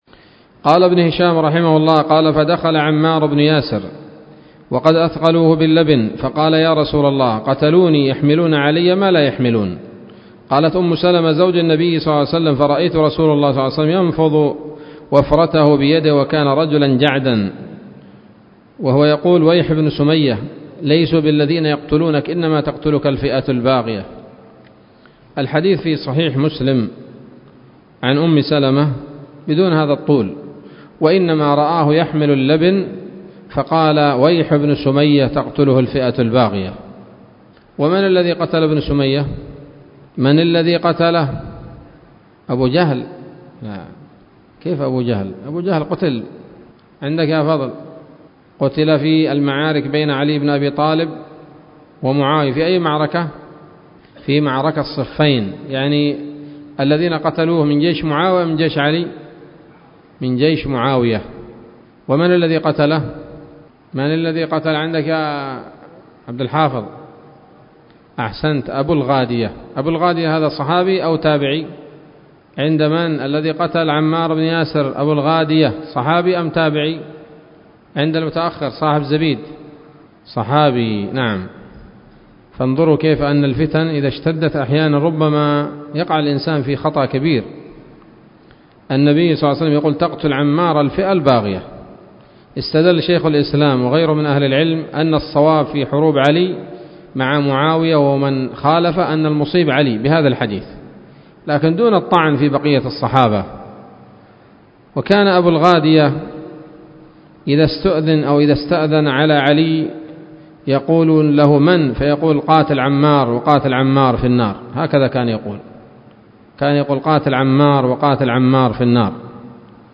الدرس السادس والسبعون من التعليق على كتاب السيرة النبوية لابن هشام